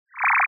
It uses an FM Synth that I wrote (Phase Modulation, more accurately) that has a carrier/modulator pair, an LFO (for either pitch or volume uses) and some frequency sweep options and an ASR envelope.
raygun1.wav